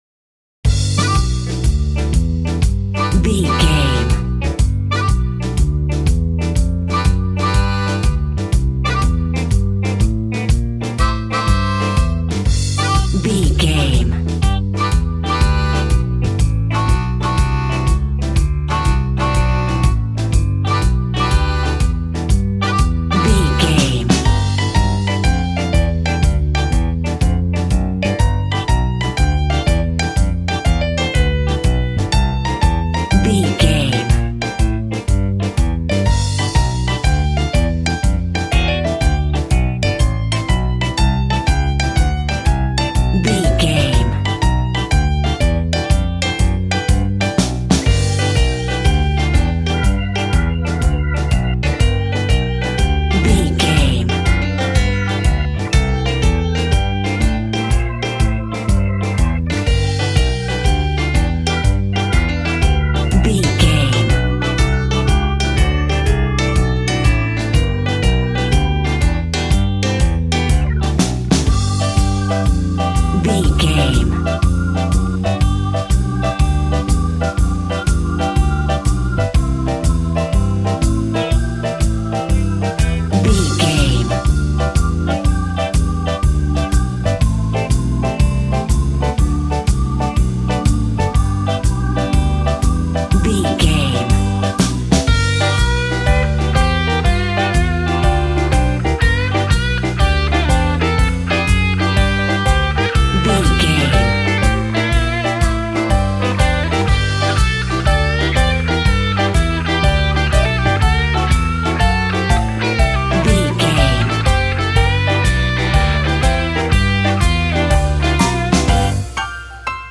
Ionian/Major
sad
mournful
bass guitar
electric guitar
electric organ
drums